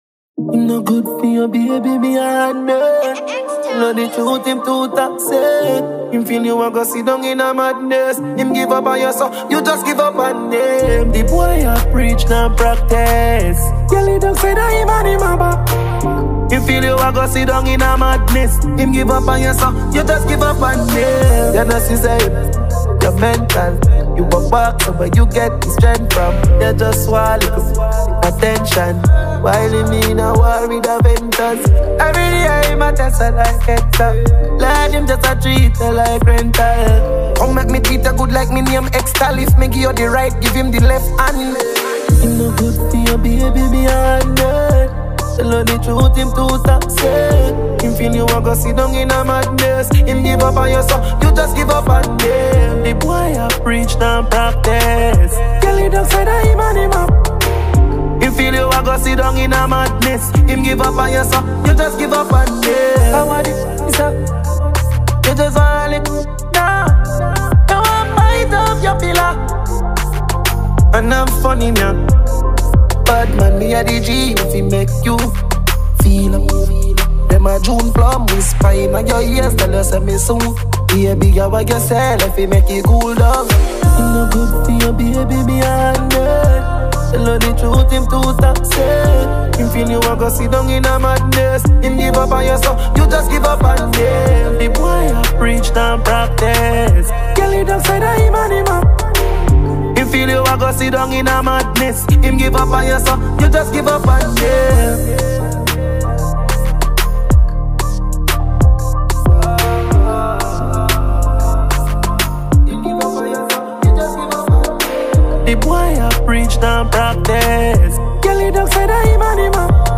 dncehall tune